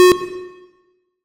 count_tone.wav